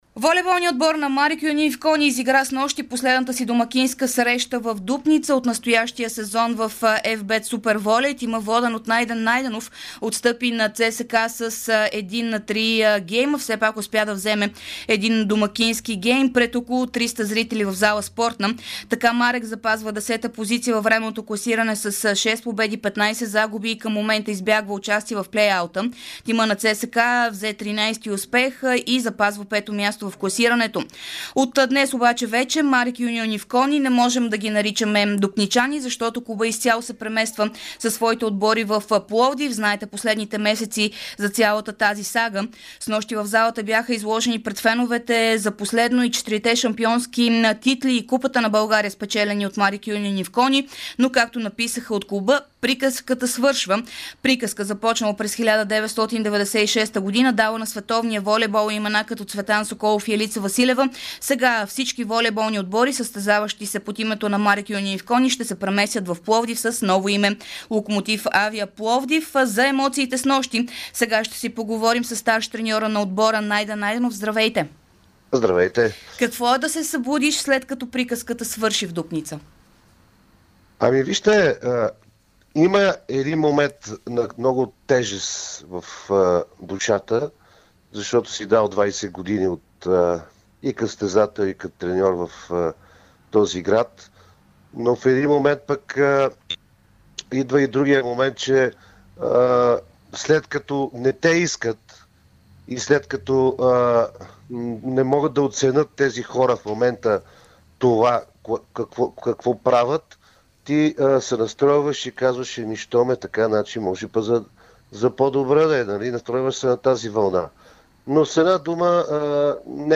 говори ексклузивно в ефира на Дарик радио след последния мач на тима в Дупница